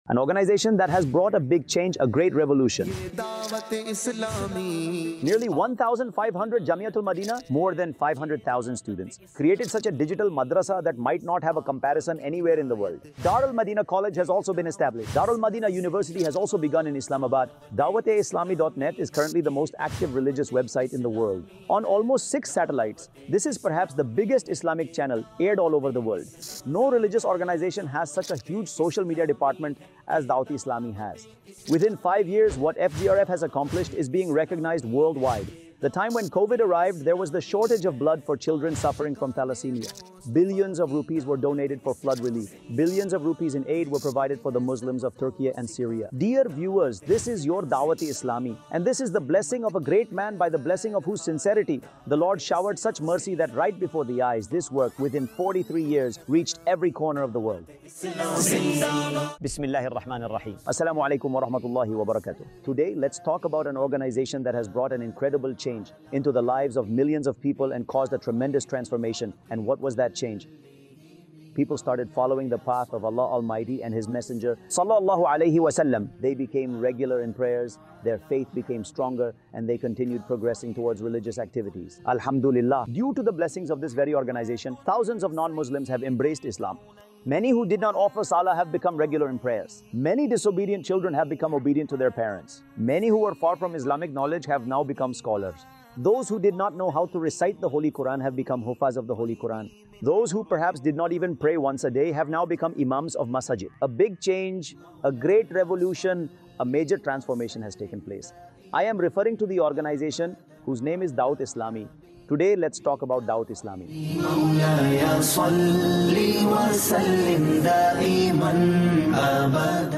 Introduction To Dawateislami | Documentary 2025 | 40 Minutes Documentary | AI Generated Audio Mar 11, 2025 MP3 MP4 MP3 Share دعوت اسلامی کا تعارف | ڈاکیومینٹری 2025 | 40 منٹس کی ڈاکیومینٹری | اے آئی جنریٹڈ آڈیو